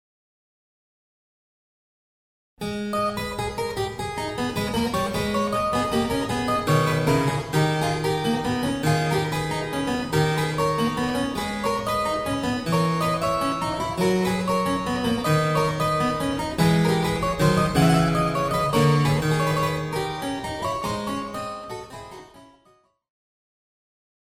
Clave italiano según / Italian harpsichord after